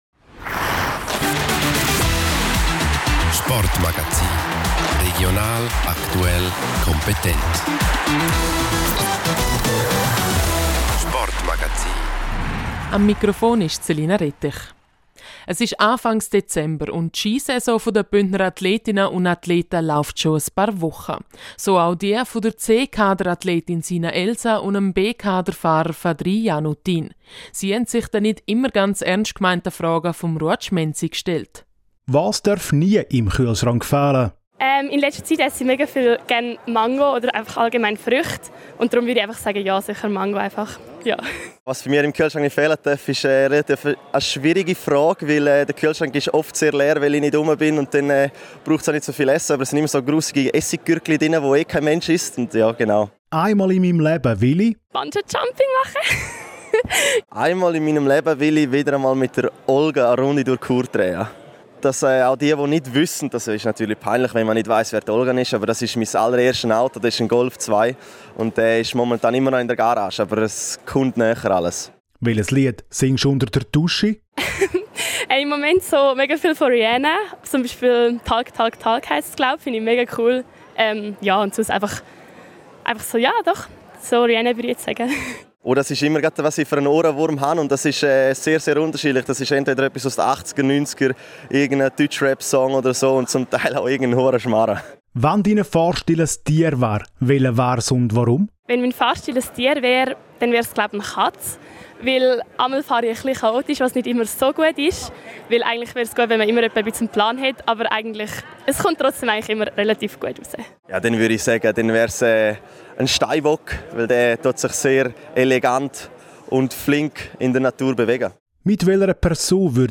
Lustiges Interview Teil 1: Bündner Ski-Cracks beantworten die etwas anderen Fragen